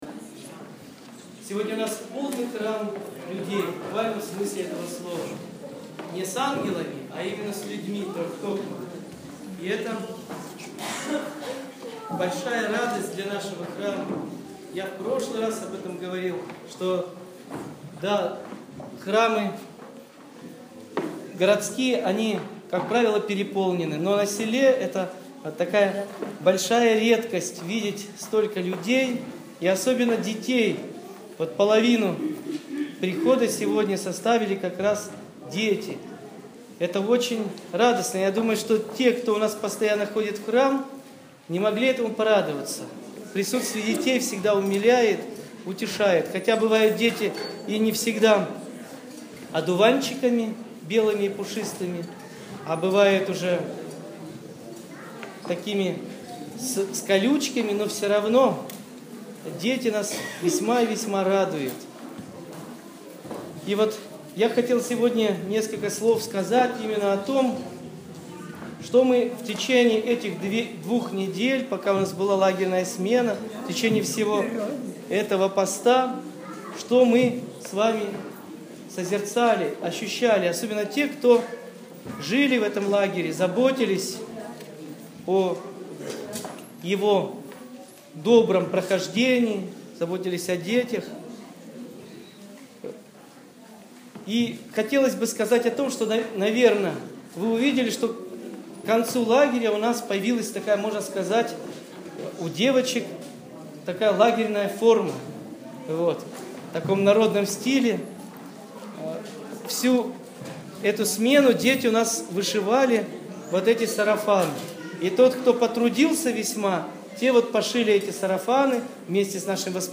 Проповедь настоятеля после Богослужений: